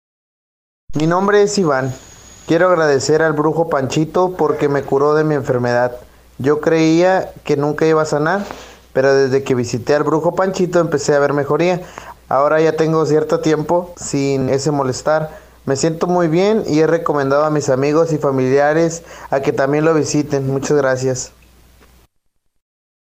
Testimonios reales